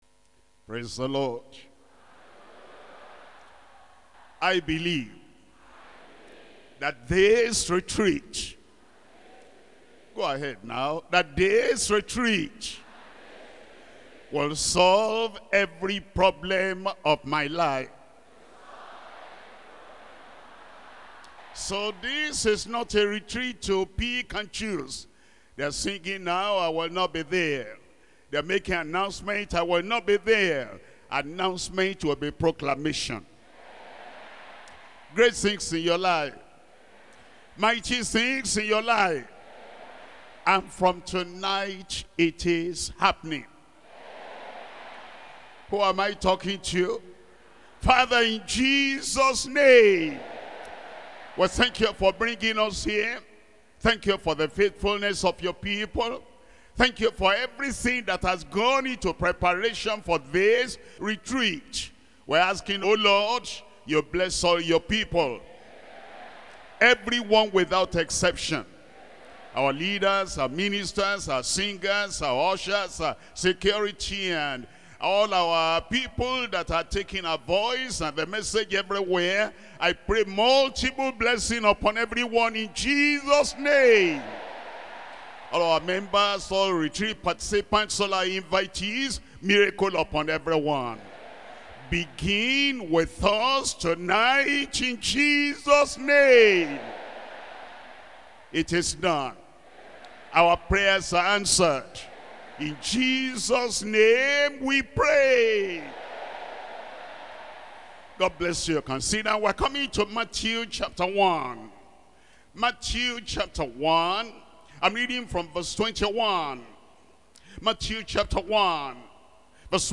Sermons - Deeper Christian Life Ministry
2025 Global December Retreat